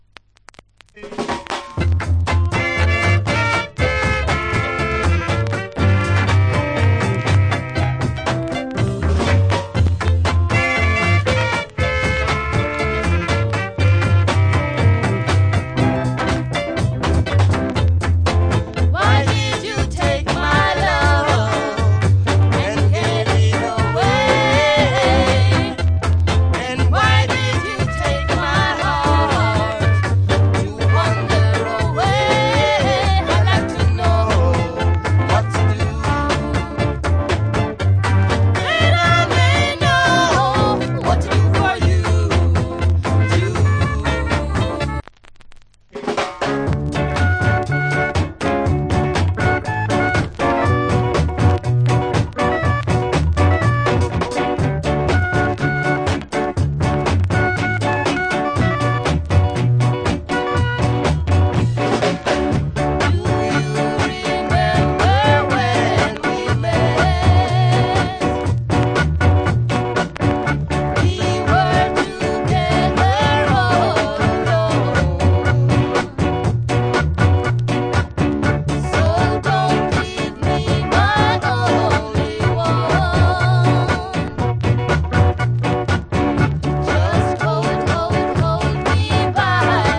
Wicked Duet Early Reggae Vocal.